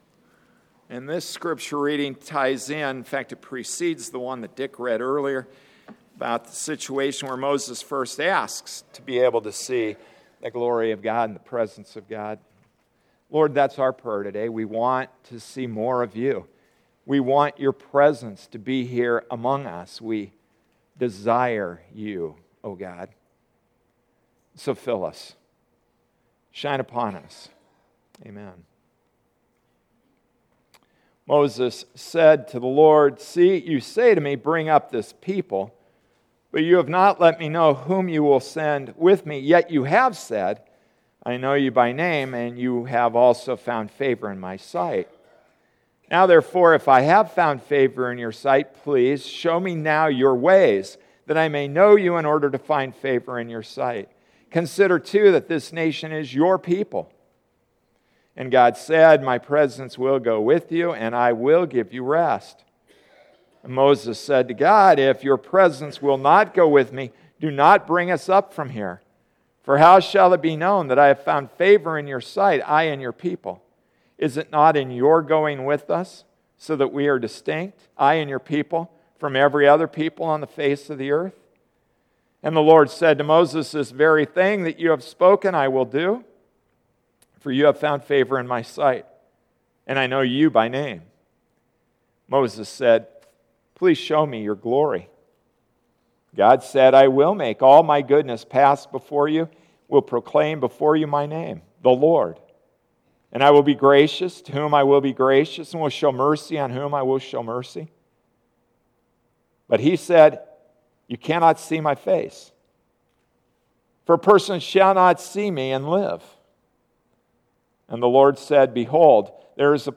September 28, 2014 Moses’s View Passage: Exodus 33:12-23 Service Type: Sunday Morning Service “Moses’ View,” Exodus 33:12-34:10 Open: A burning bush, commands on a mountaintop, smoke and fire, plagues and deliverance.